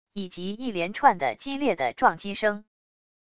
以及一连串的激烈的撞击声wav音频